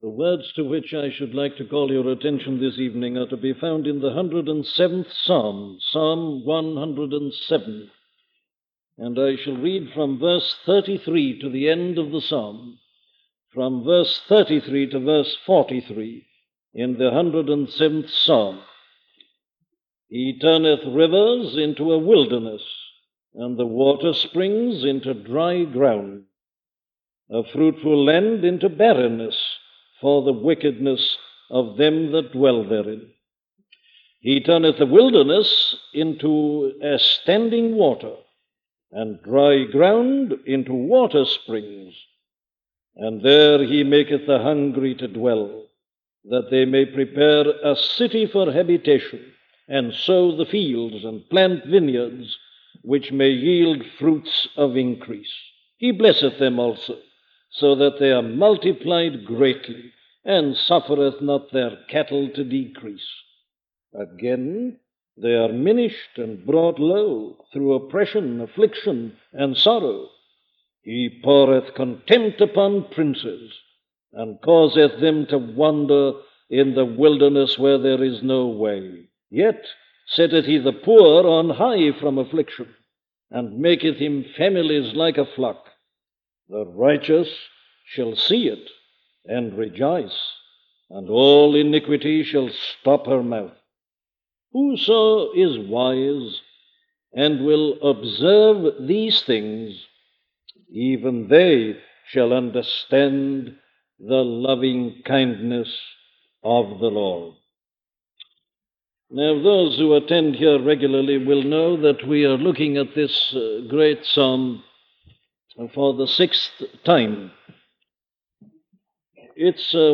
Free Sermon | Book of Psalms | Chapter 107